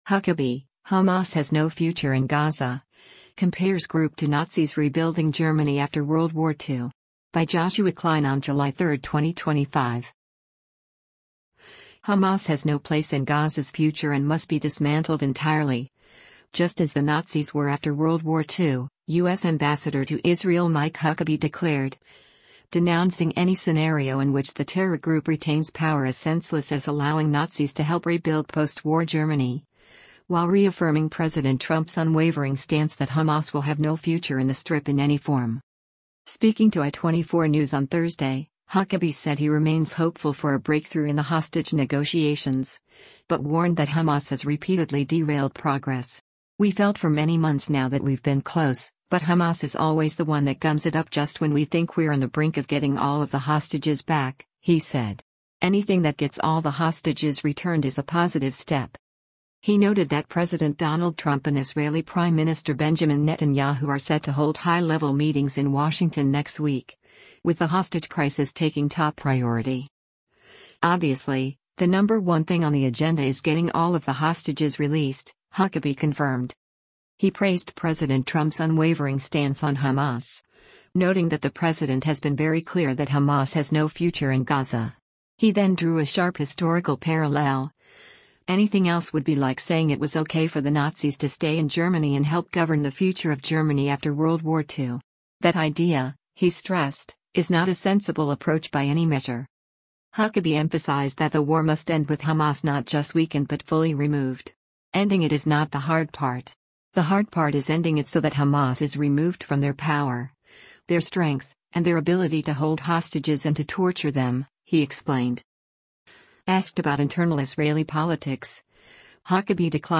Speaking to i24 News on Thursday, Huckabee said he remains hopeful for a breakthrough in the hostage negotiations, but warned that Hamas has repeatedly derailed progress.